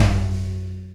Tom Shard 02.wav